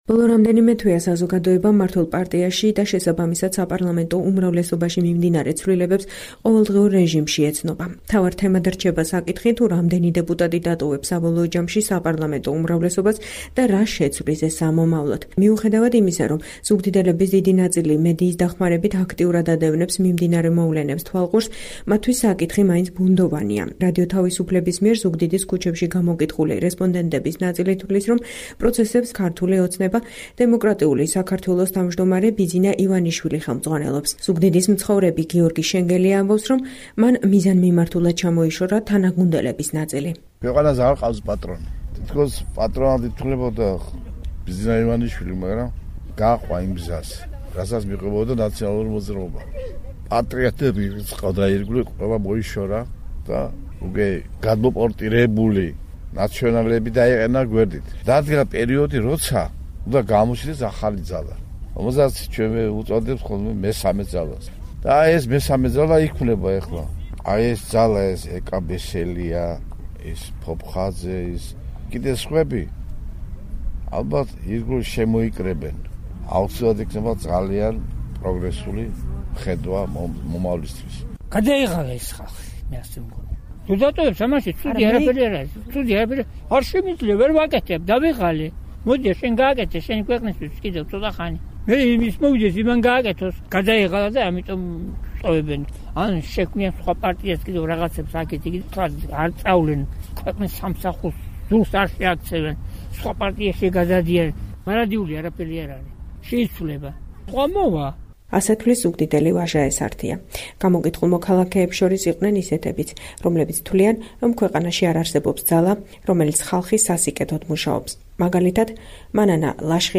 რადიო თავისუფლების მიერ ზუგდიდის ქუჩებში გამოკითხული რესპონდენტების ნაწილი ფიქრობს, რომ პროცესებს, მათ შორის 7 წევრის მიერ უმრავლესობის დატოვებასაც, „ქართული ოცნება - დემოკრატიული საქართველოს“ თავმჯდომარე ბიძინა ივანიშვილი ხელმძღვანელობს.